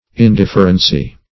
Indifferency \In*dif"fer*en*cy\, n.